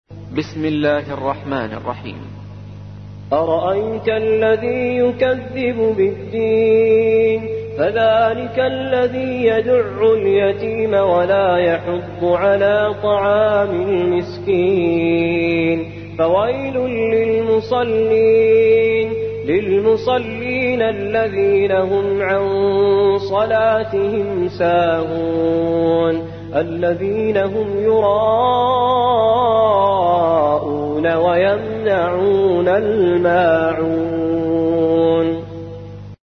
107. سورة الماعون / القارئ